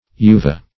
Search Result for " uva" : The Collaborative International Dictionary of English v.0.48: Uva \U"va\, n. [L., a grape.]